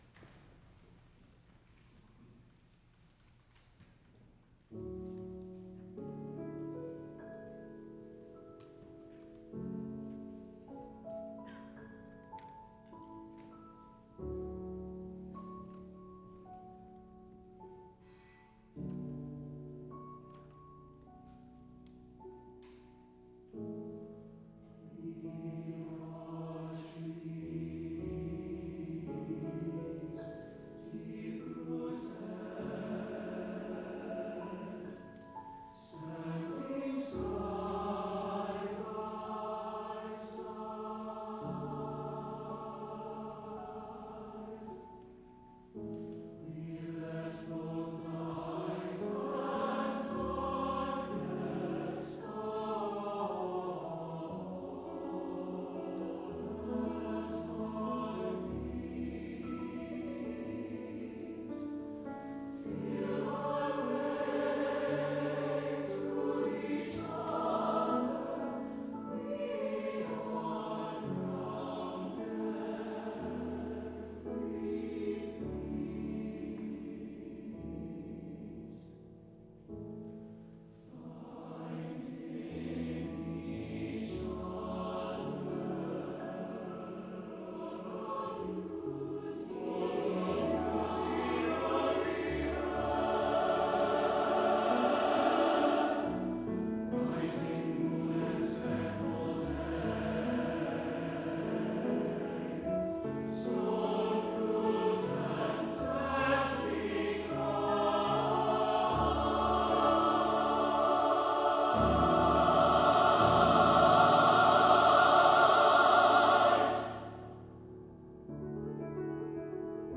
for SATB div. choir, piano